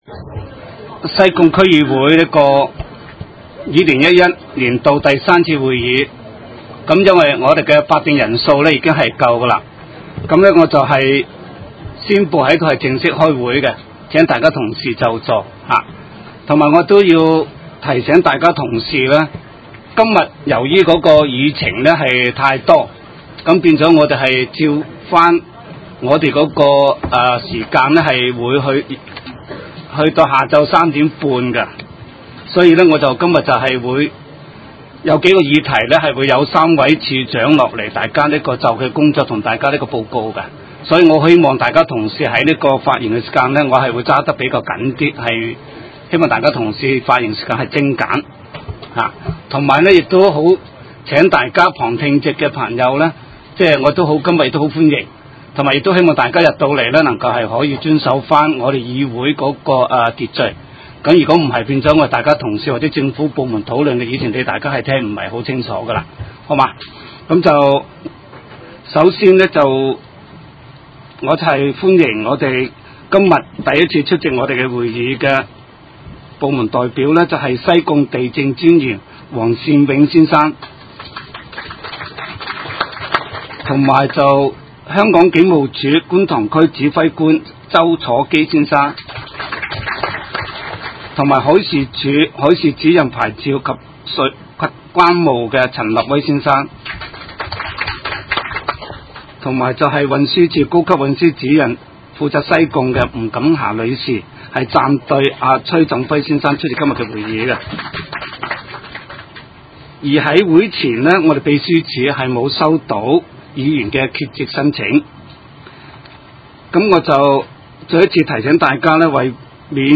西貢區議會二○一一年第 三次會議